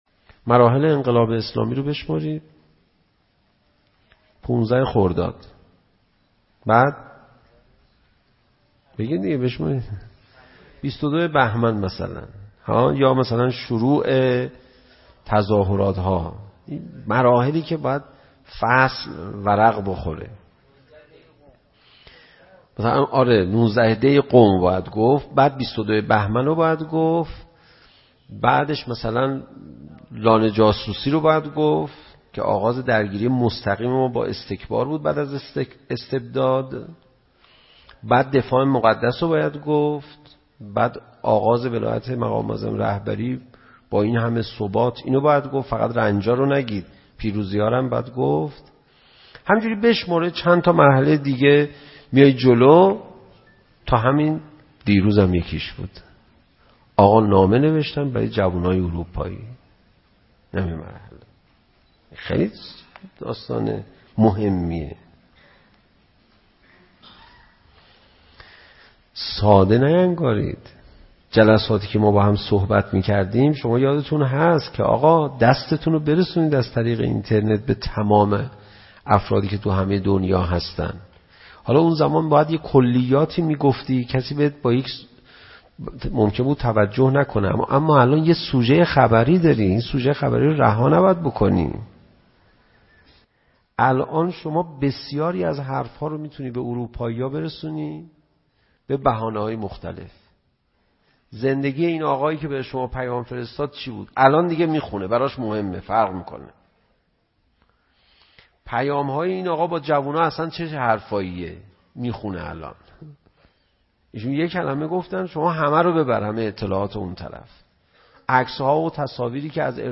letter4u-sokhanrani-panahian.mp3